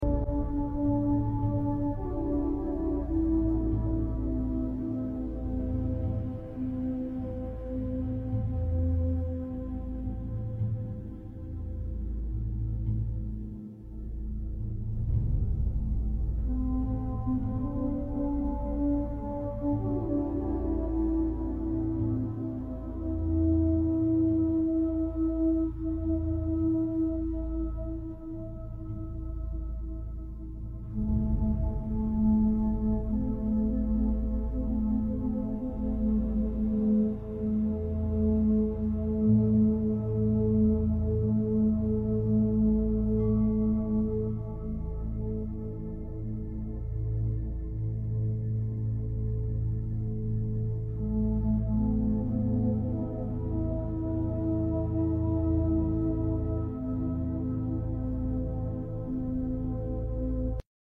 Incrementa tu energía femenina al escuchar esta hermosa frecuencia. Siéntete bella, saludable y amada.